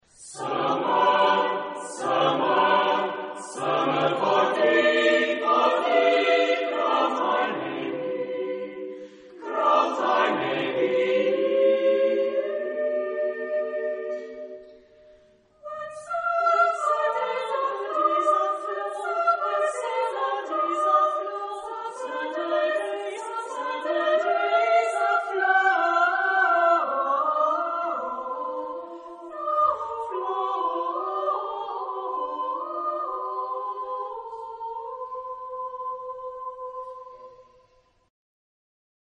SATB (4 voix mixtes).
Profane. contemporain.